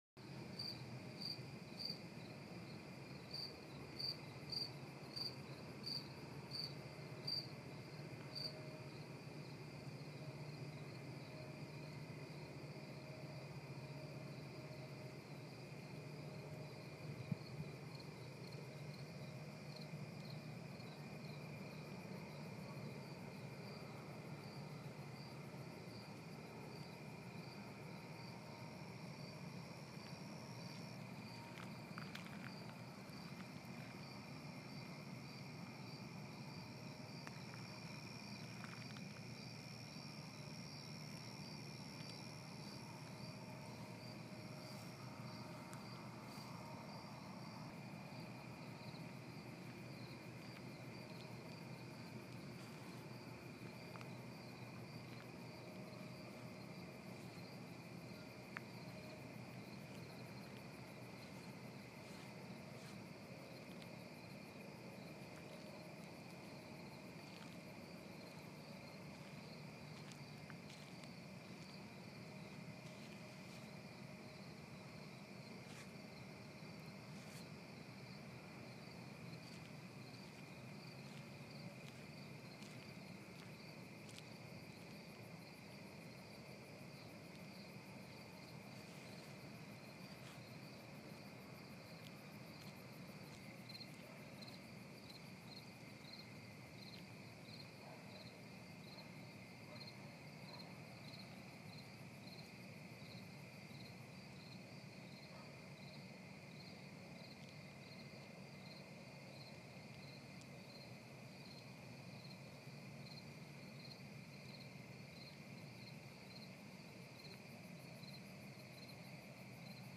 Night-Sounds-10-Minutes-2.mp3